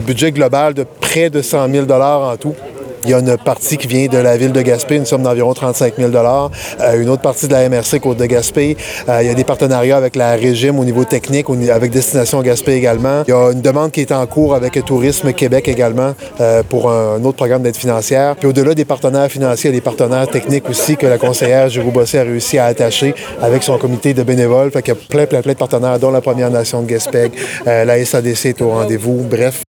Le maire de Gaspé, Daniel Côté, rappelle que la mise en place du service découle d’un effort collectif et communautaire :